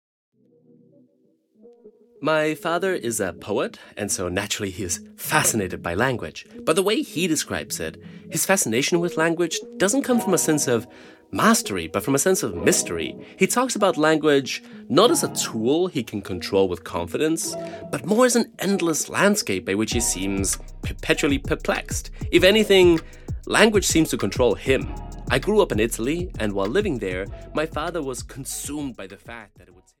Spellbound (EN) audiokniha
Ukázka z knihy